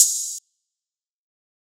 Phones OH.wav